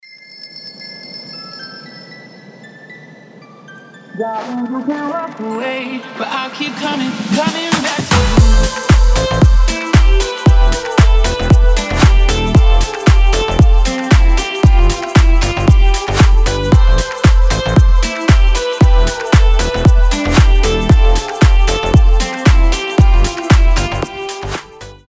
танцевальные
нарастающие